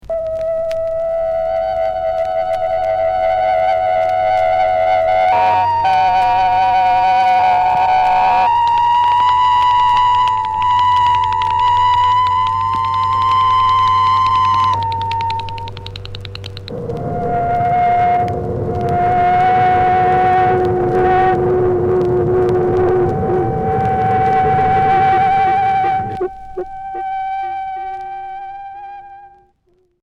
Poésie sonore expérimental e